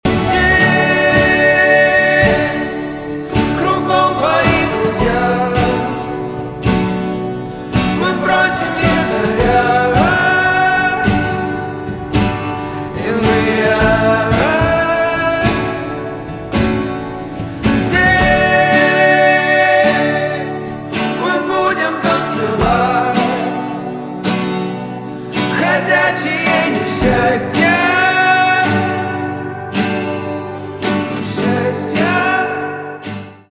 инди
чувственные , гитара